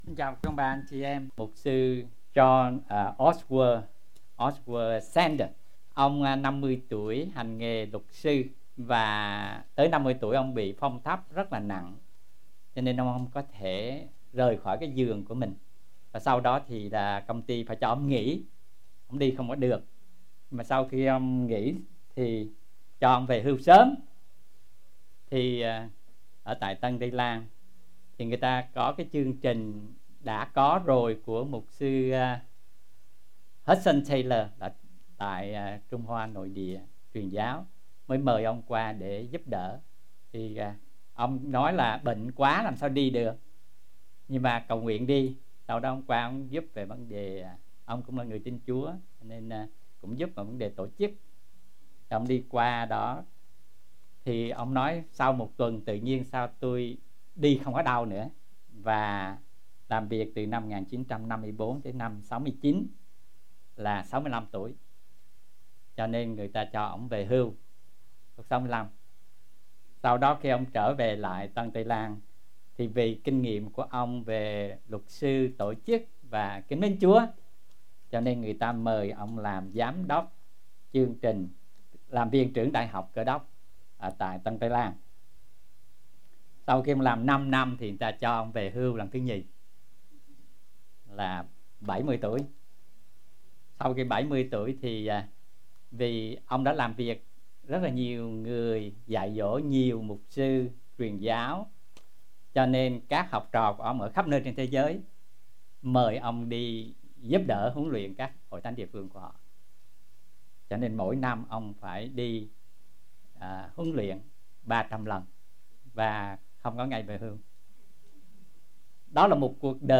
Bài Giảng | Hội Thánh Tin Lành Austin